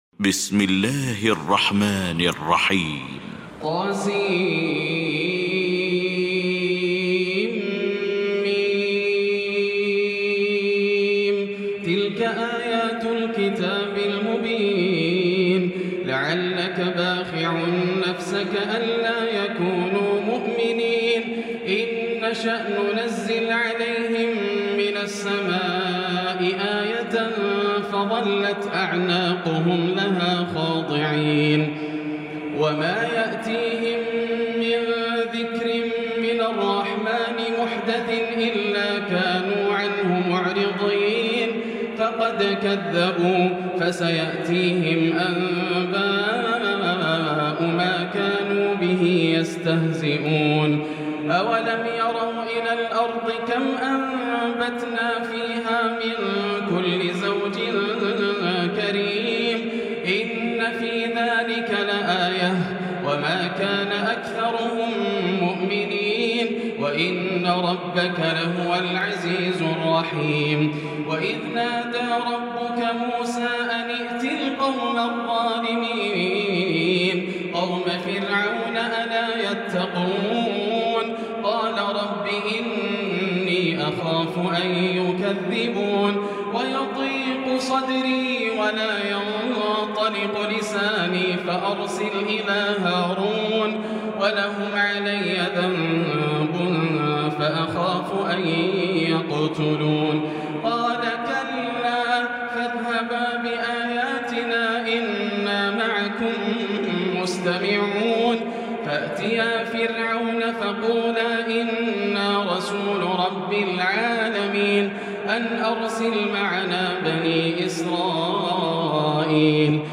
المكان: المسجد الحرام الشيخ: معالي الشيخ أ.د. بندر بليلة معالي الشيخ أ.د. بندر بليلة فضيلة الشيخ ياسر الدوسري الشعراء